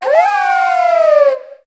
Cri de Lougaroc dans sa forme Nocturne dans Pokémon HOME.
Cri_0745_Nocturne_HOME.ogg